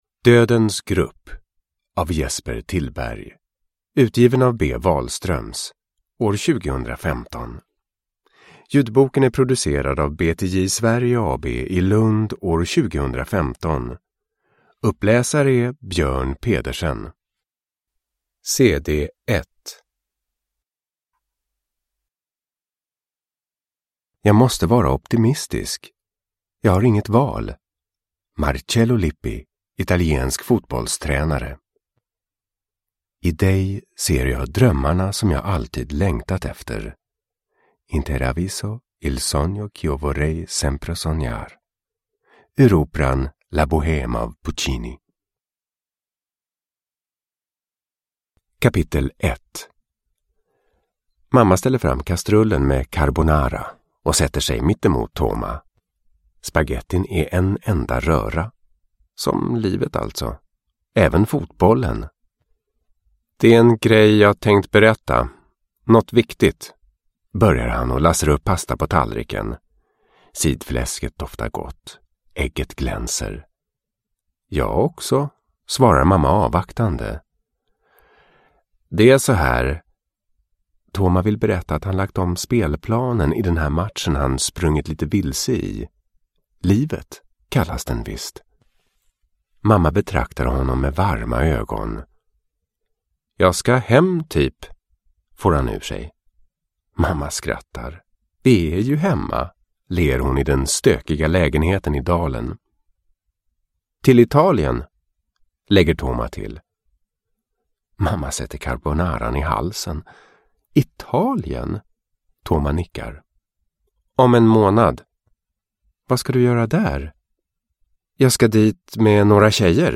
Dödens grupp – Ljudbok – Laddas ner